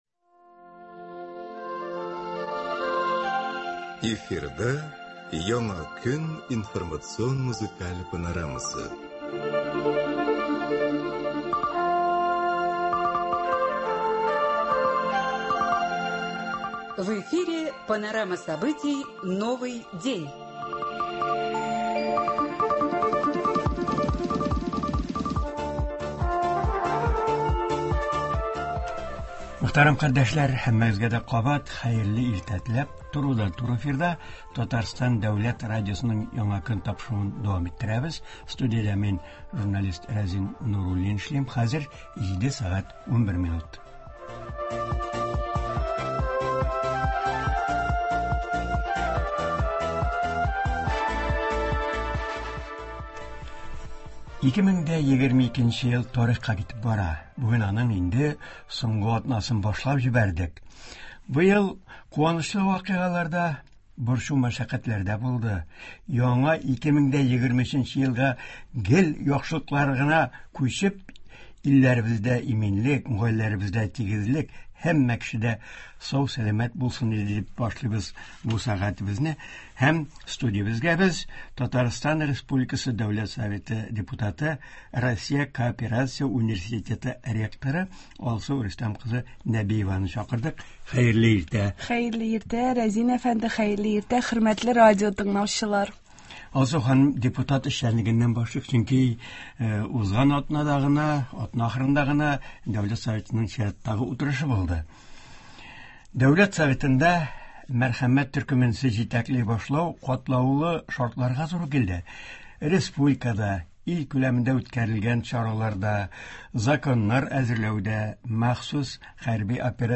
Болар хакында турыдан-туры эфирда Татарстан республикасы Дәүләт Советының “Мәрхәмәт”-“Милосердие” төркеме җитәкчесе, Мәскәү кооперация университеты ректоры Алсу Нәбиева сөйләячәк, тыңлаучылар сорауларына җавап бирәчәк һәм Яңа елга планнары белән уртаклашачак.